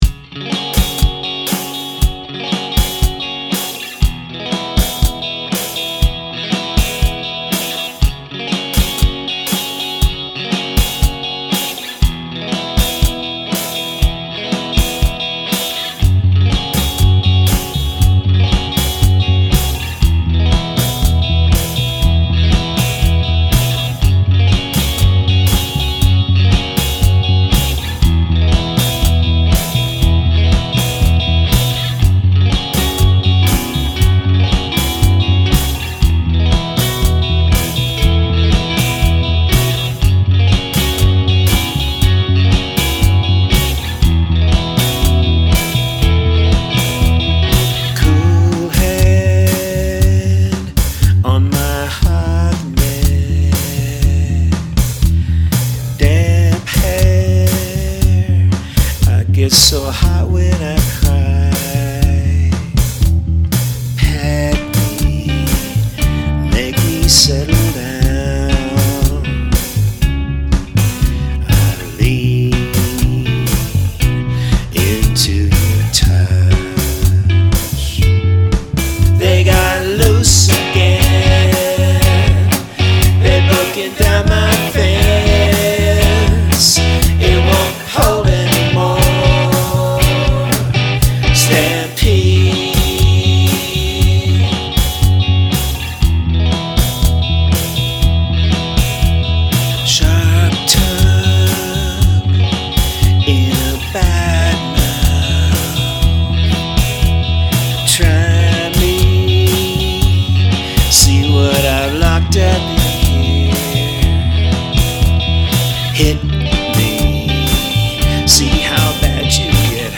But I wanted to share these lo-fi recordings of works in progress with our community, because who knows if I’ll ever have time to polish them for mass consumption?
I started composing most of them with the electric bass